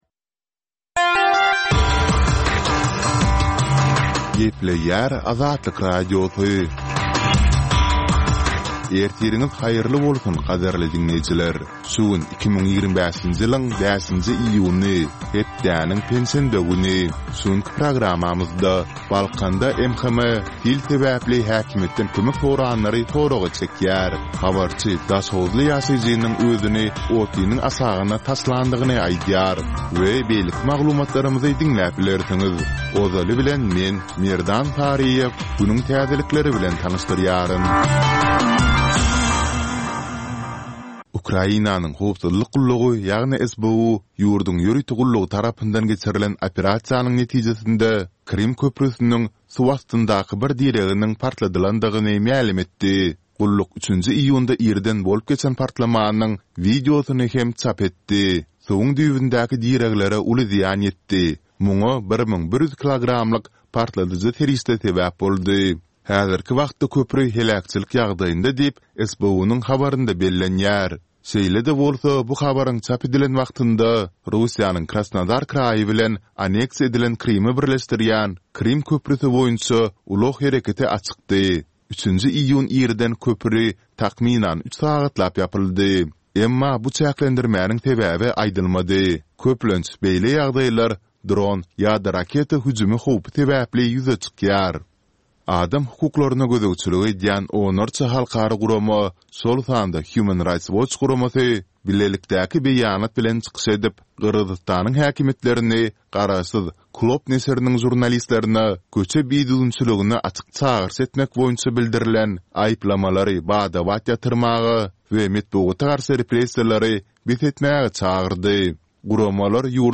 Täzelikler